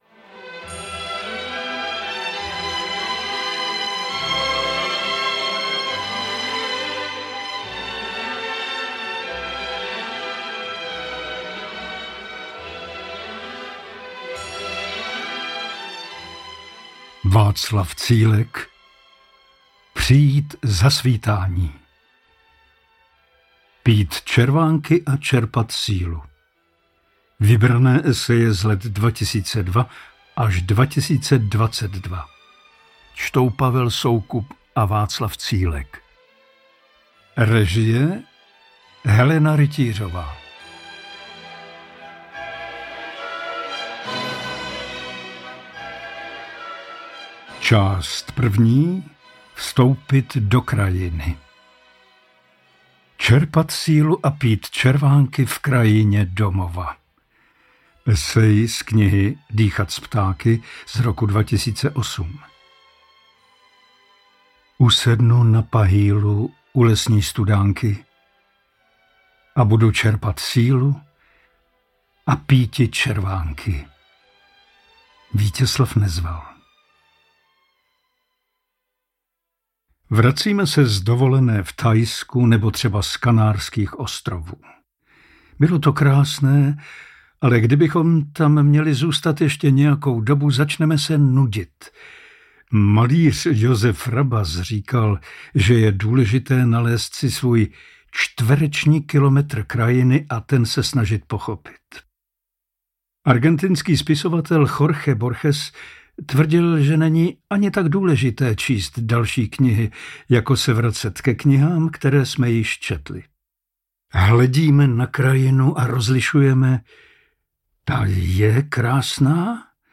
Interpreti:  Václav Cílek, Pavel Soukup, Pavel Soukup
Knižní bestseller mapující jeho dvacetiletou autorskou činnost získal audiální podobu díky skvělé interpretaci herce Pavla Soukupa.